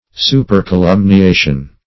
Search Result for " supercolumniation" : The Collaborative International Dictionary of English v.0.48: Supercolumniation \Su`per*co*lum`ni*a"tion\, n. (Arch.)